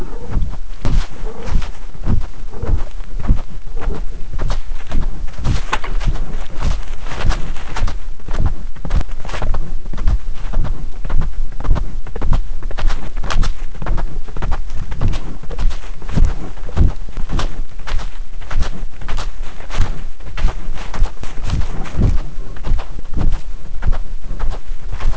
Through a collaborative effort between computer scientists, engineers, and zoologists, custom designed acoustic bio-loggers were fitted to eight lions and recorded audio simultaneously with accelerometer and magnetometer data.